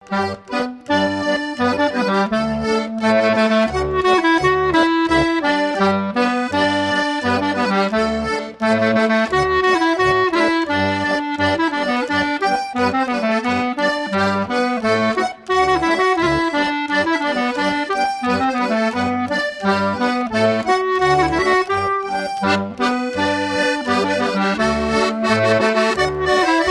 Scottishs - Scottish Saint Antoine
danse : scottich trois pas